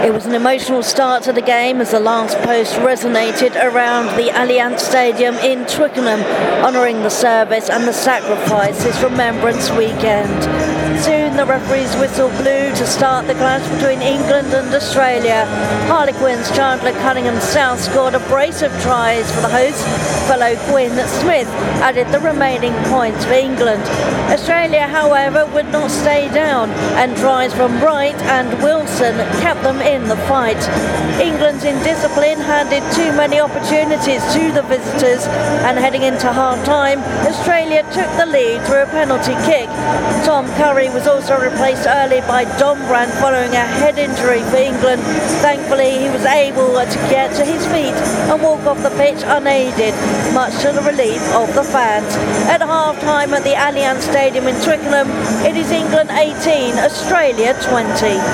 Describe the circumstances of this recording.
Half-Time at the Allianz Stadium in Twickenham: England 18 – 20 Australia